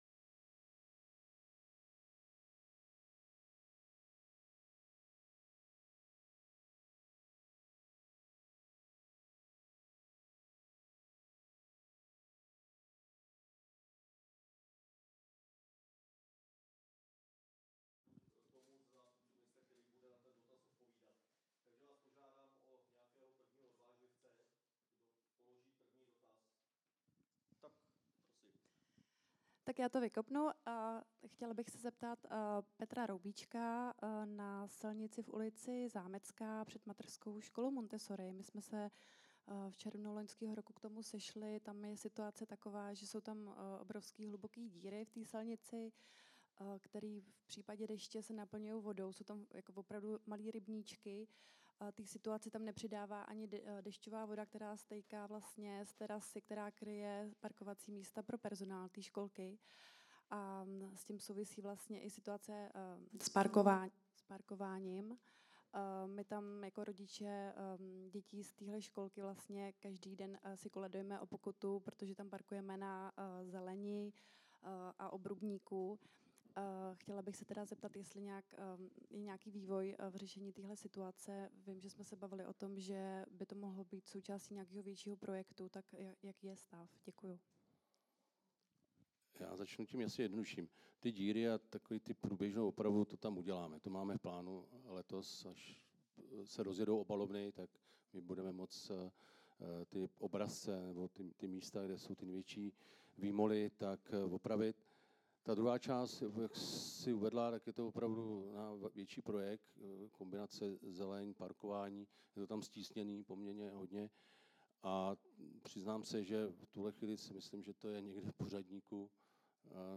Setkání s obyvateli města v jednotlivých lokalitách.
V první části občané pokládali dotazy, navrhovali a diskutovali s politiky na různá témata. Ve druhé části se zástupci magistrátu ptali občanů na témata dopravy, zástavby, veřejných prostor atd.